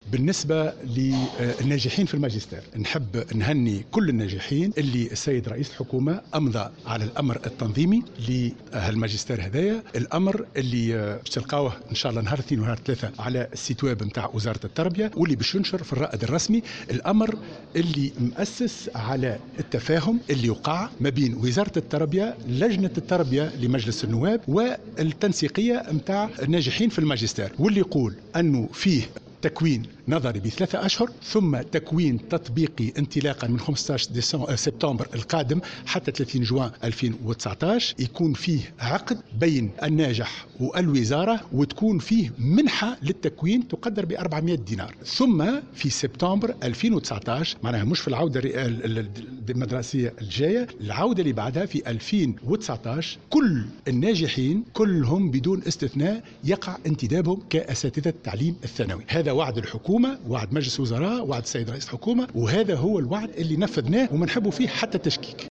أكد وزير التربية حاتم بن سالم اليوم الجمعة خلال جلسة عامة انتظمت بمقر مجلس نواب الشعب أن الأمر الحكومي المتعلق بانتداب الناجحين في مناظرة الماجستير المهني في علوم التربية قد تم إمضاءه من طرف رئيس الحكومة وسيتم نشره خلال الأيام الأولى من الأسبوع القادم على الموقع الرسمي لوزارة التربية.